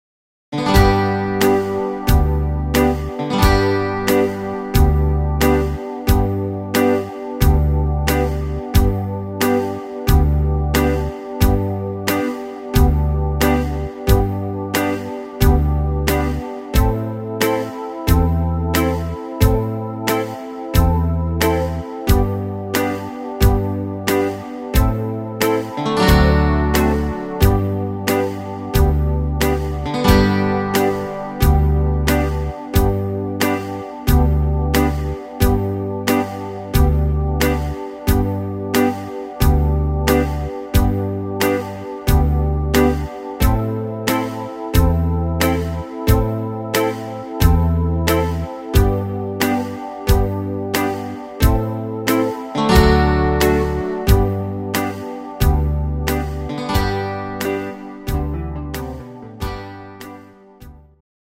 instr. Bouzouki